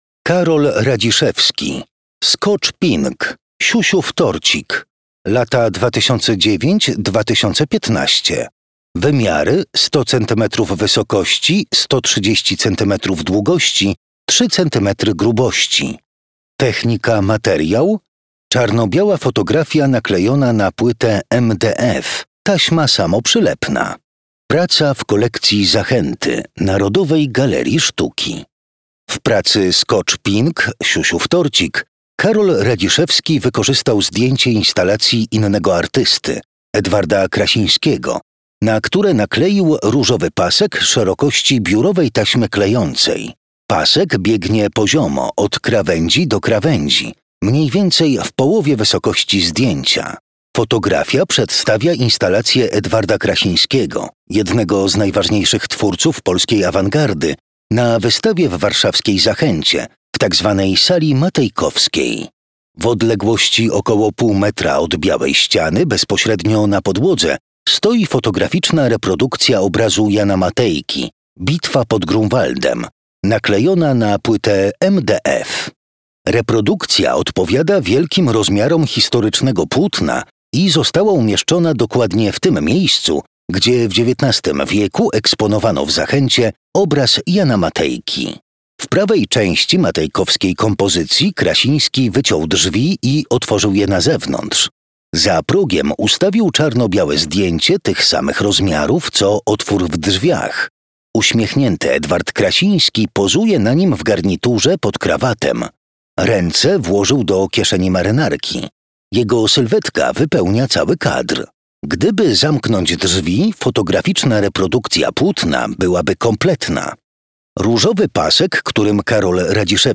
audiodeskrypcja